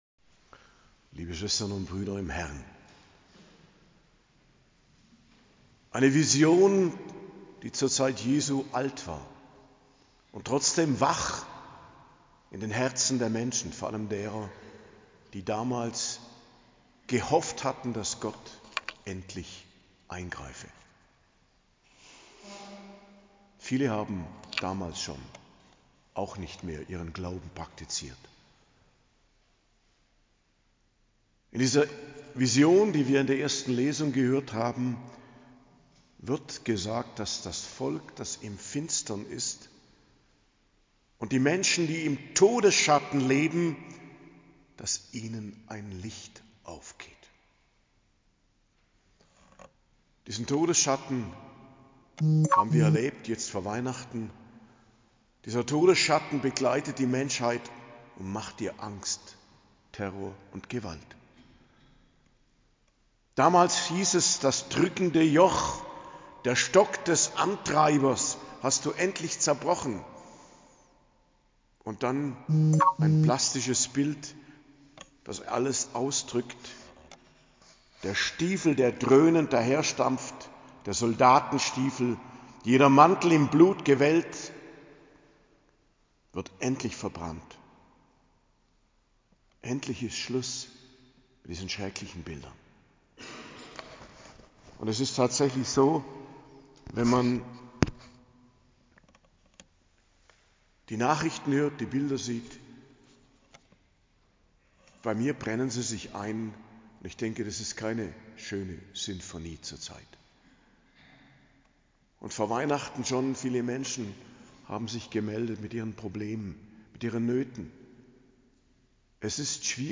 Predigt zu Weihnachten - Hochfest der Geburt des Herrn - In der Heiligen Nacht, 24.12.2024 ~ Geistliches Zentrum Kloster Heiligkreuztal Podcast